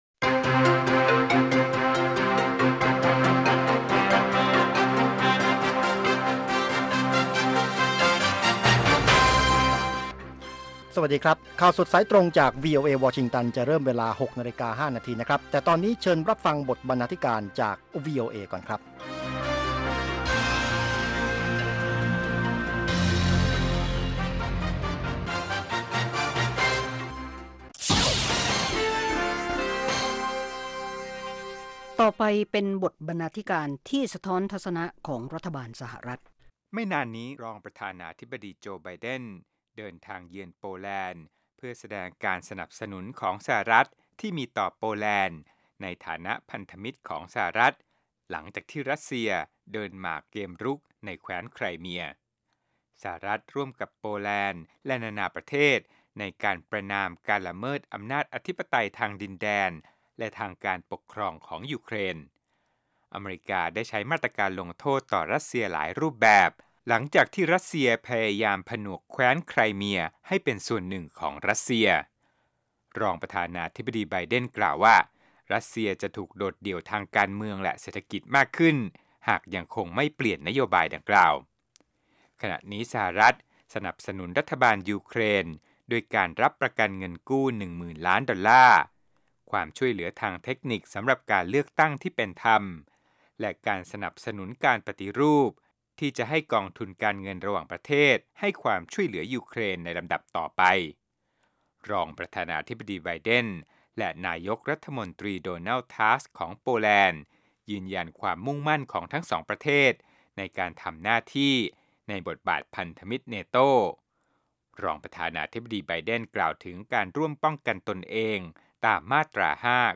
ข่าวสดสายตรงจากวีโอเอ ภาคภาษาไทย 6:00 – 6:30 น.